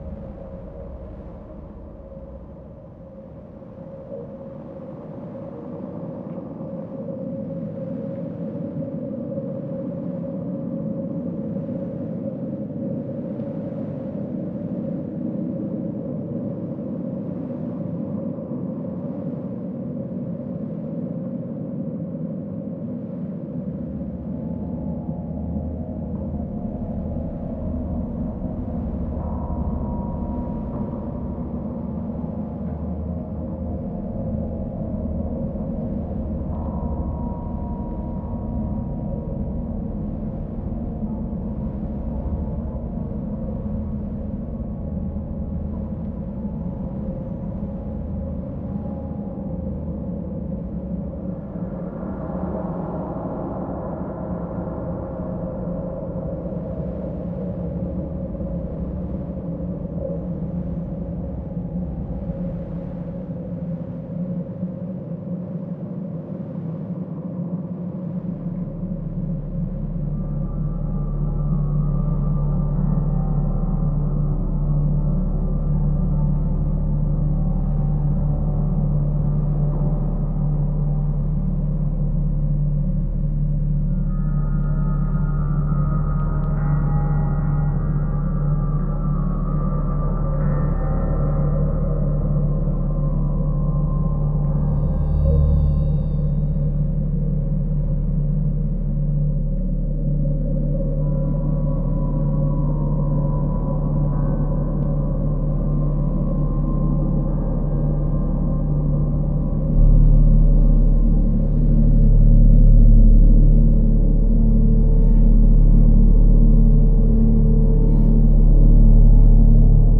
dark ambient